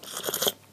stamina_sip.1.ogg